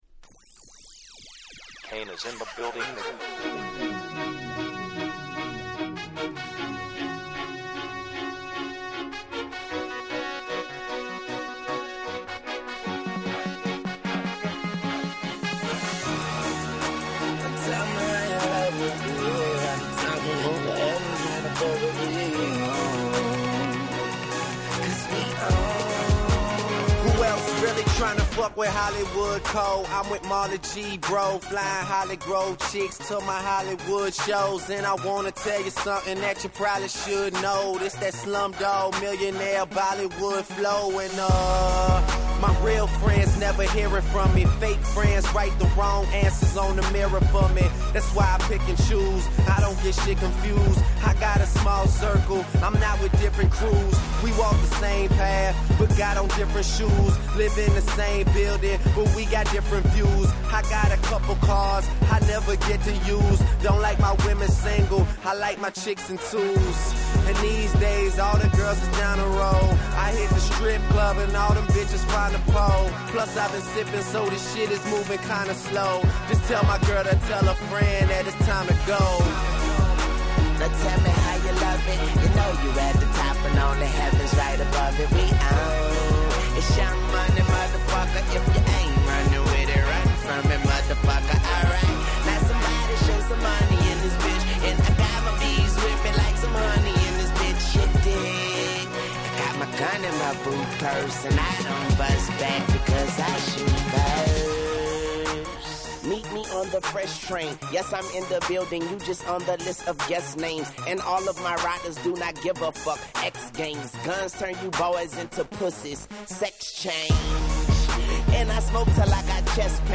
10' Super Hit Hip Hop !!